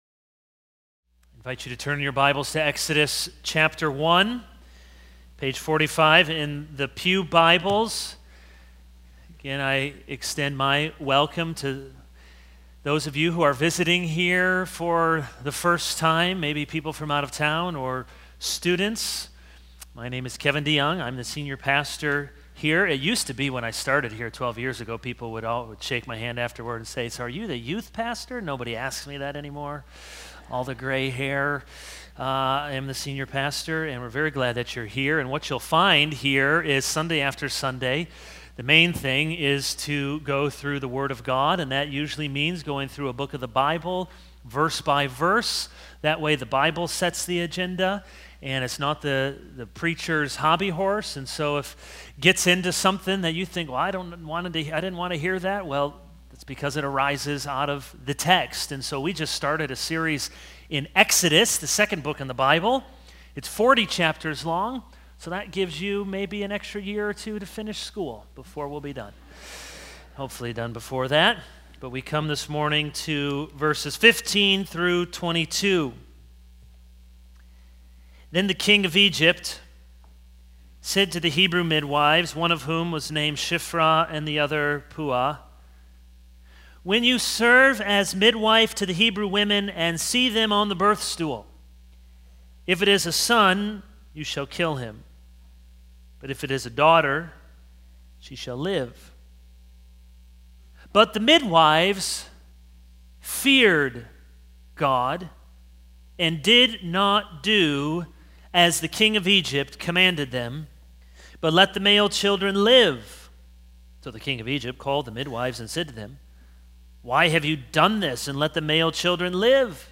This is a sermon on Exodus 1:15-22.